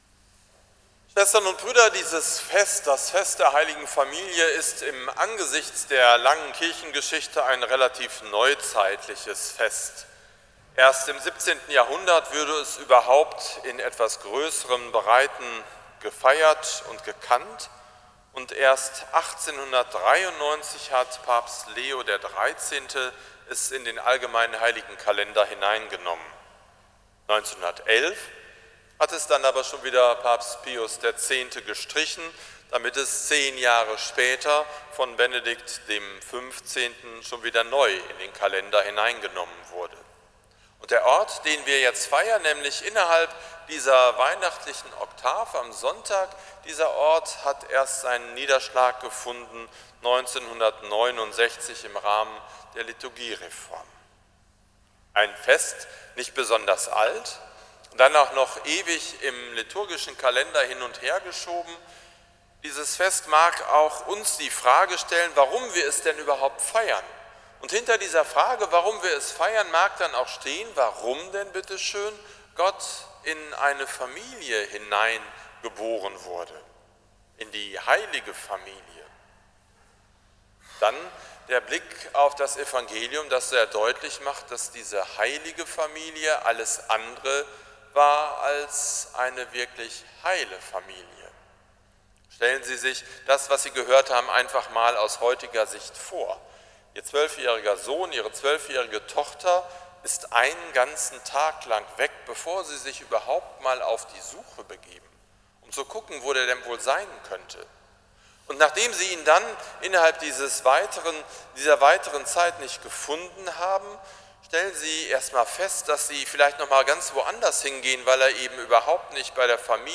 Predigt zum Fest der Hl. Familie 2018 – St. Nikolaus Münster
predigt-zum-fest-der-hl-familie-2018